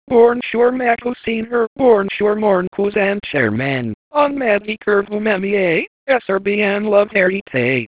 Les paroles ont été créés par synthèse vocale (Text-to-Speech Synthesis),